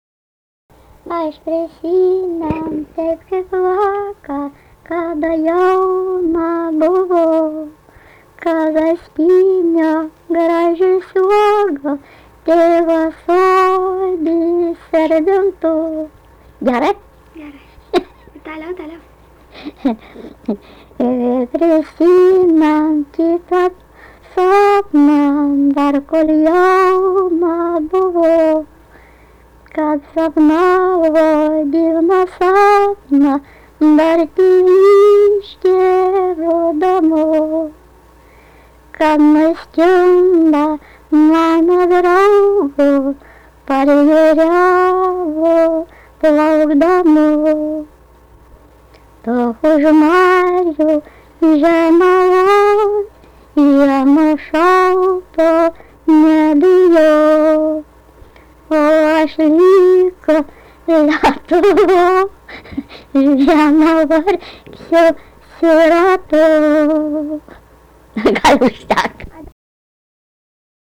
daina, vestuvių
Erdvinė aprėptis Jasiuliškiai
Atlikimo pubūdis vokalinis